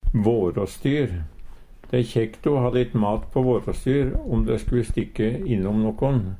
våråstyr - Numedalsmål (en-US)